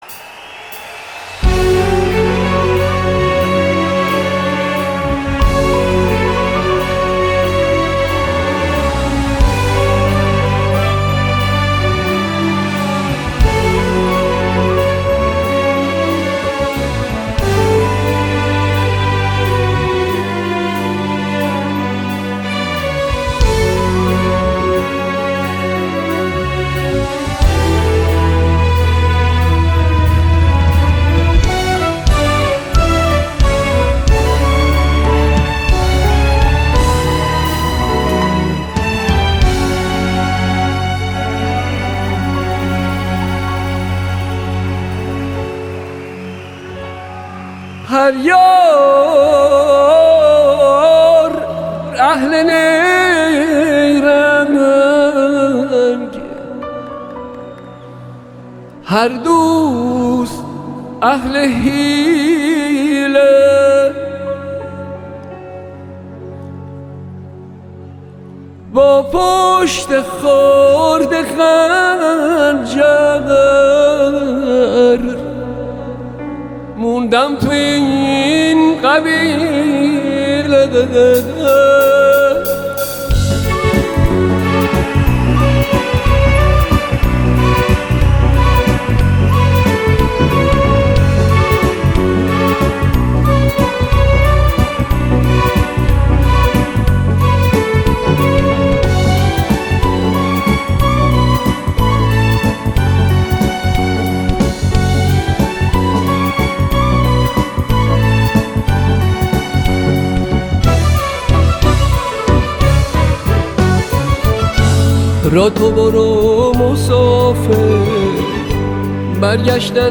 در کنسرت ملبورن استرالیا